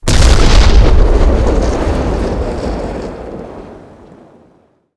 ExpMissileLarge.wav